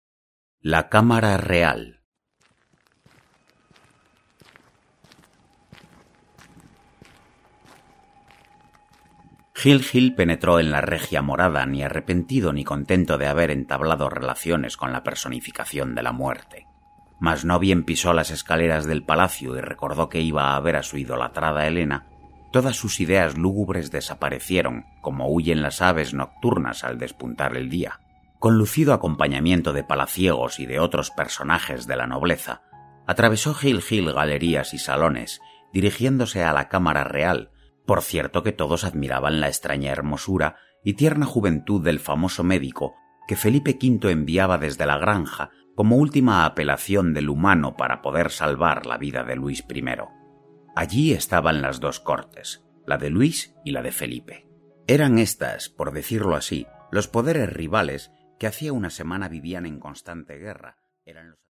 Deseamos que esta adaptación sonora cumpla con creces todas sus expectativas.